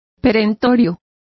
Complete with pronunciation of the translation of peremptory.